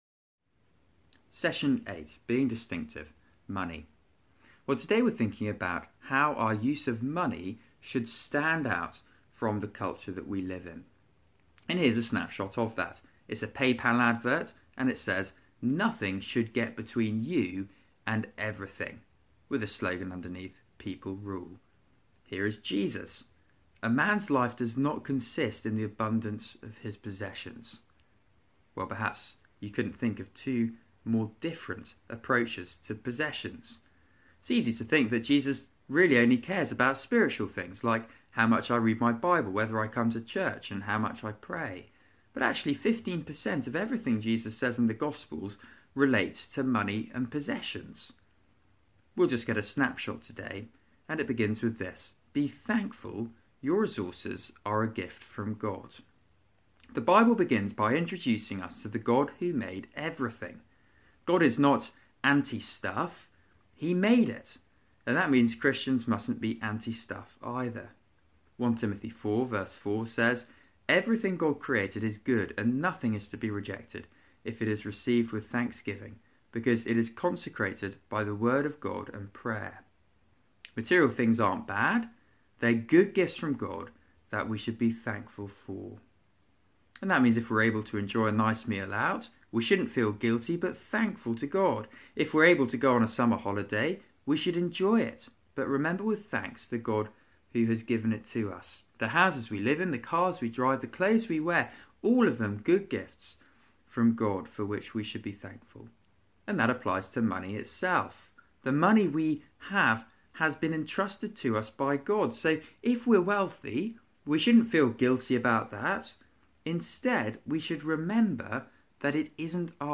Media for Christian Foundations on Mon 20th Nov 2017 19:30 Speaker: [unset] Passage: Series: Discipleship Course- Being God's people today Theme: Being distinctive: money Talk Search the media library There are recordings here going back several years.